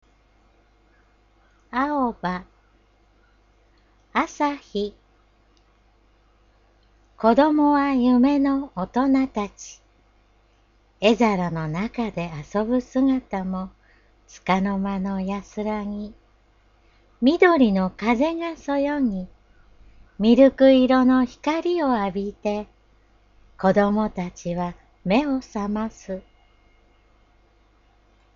朗読